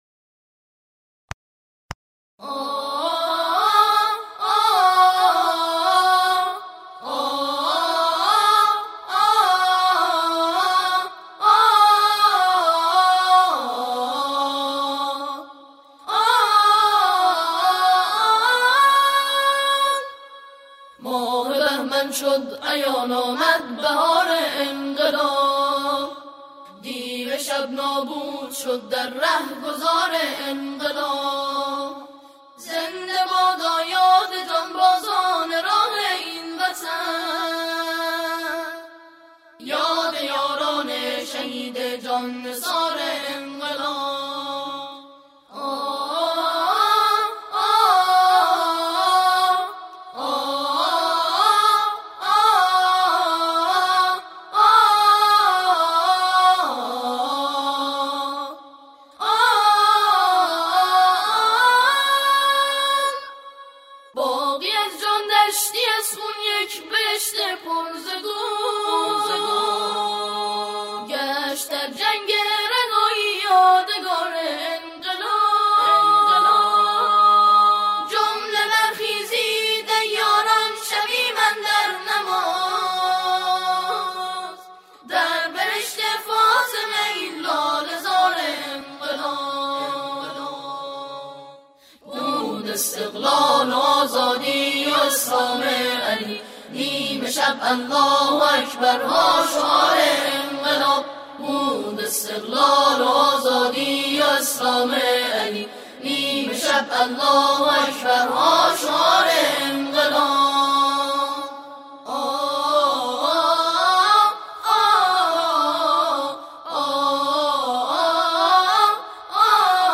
نسخه آکاپلا